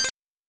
HandScannerError.wav